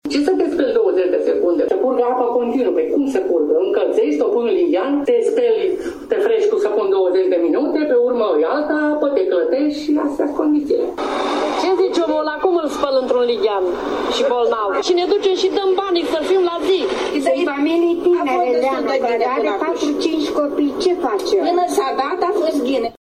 VOXURI-FARA-APA_1.mp3